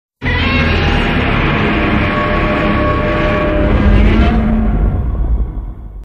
Godzilla 2000 Roar.mp3